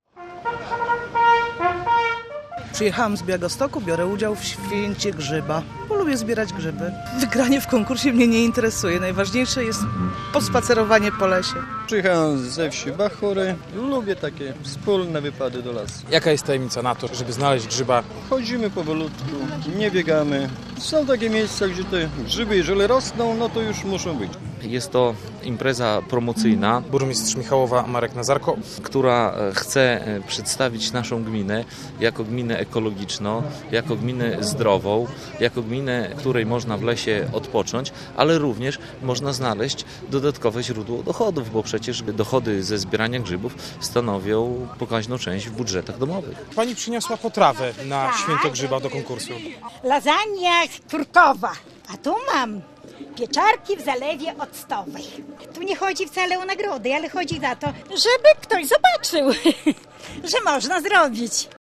Święto grzyba w Michałowie - relacja
Jednak najważniejsze elementy Święta Grzyba to zawody grzybiarzy oraz konkurs na najsmaczniejszą potrawę grzybową. Jak co roku, wymarszowi w poszukiwaniu grzybów towarzyszył hejnał.